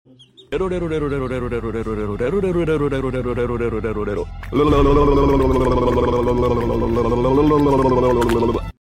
What do you think a chick sounds like while drinking?